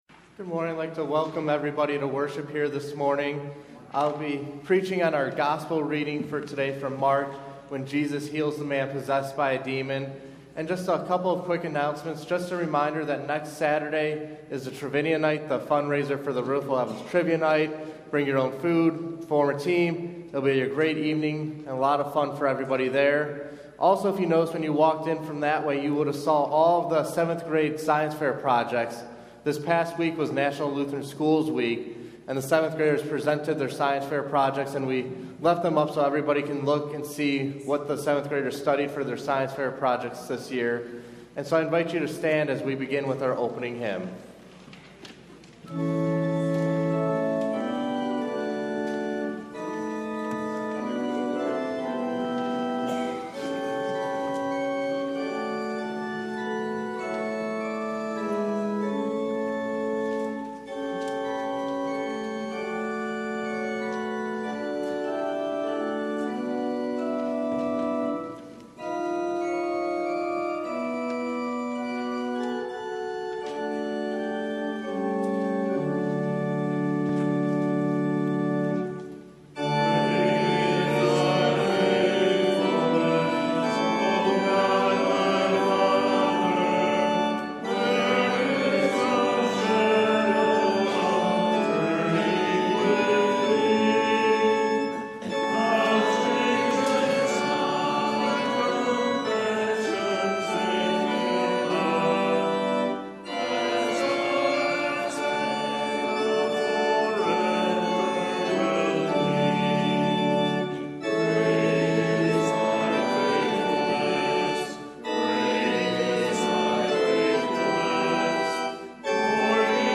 Jan 28 / Divine – Looking For Clarity – Lutheran Worship audio